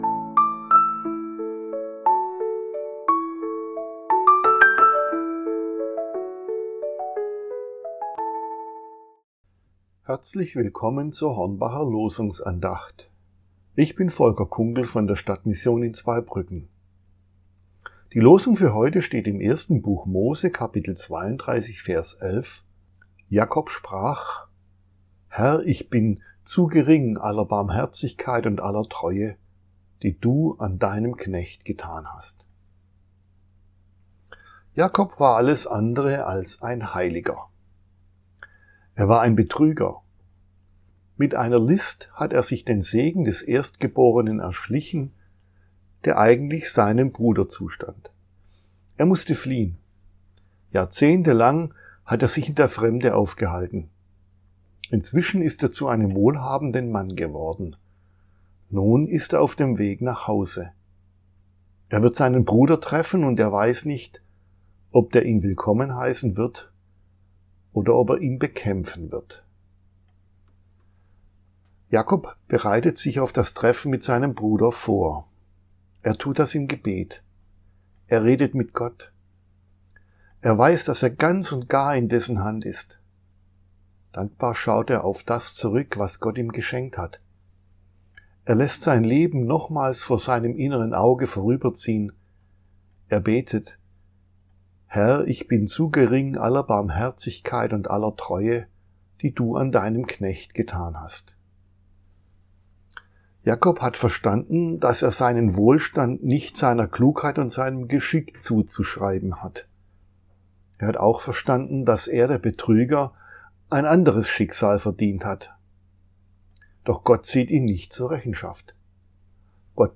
Losungsandacht für Dienstag, 10.02.2026